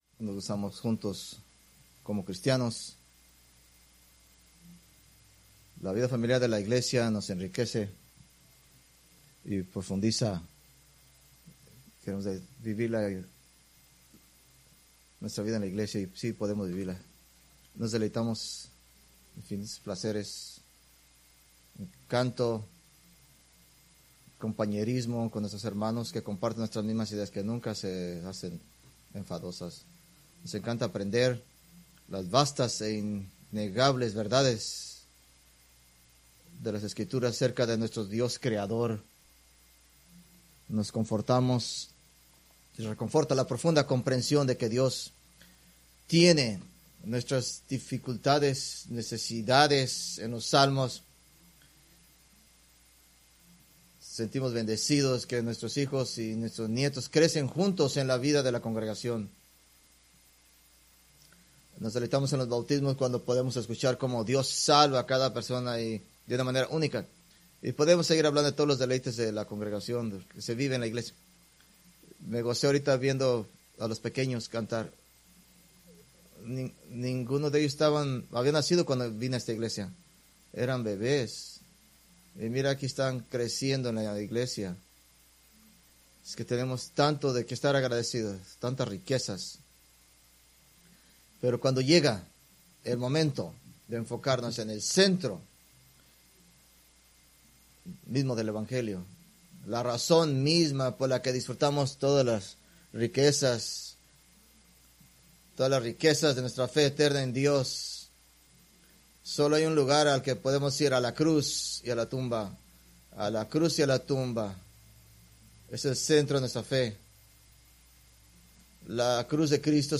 Preached April 13, 2025 from Marcos 15:1-20